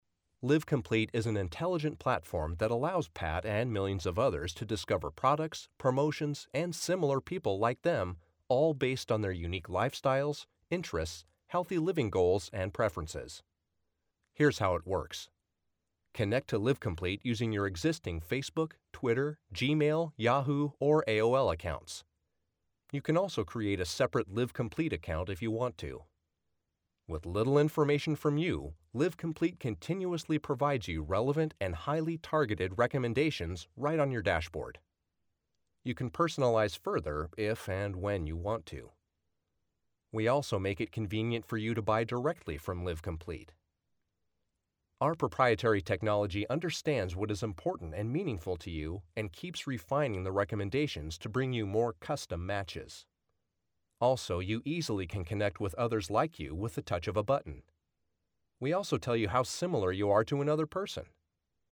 warm, friendly, guy next door, corporate
Sprechprobe: Industrie (Muttersprache):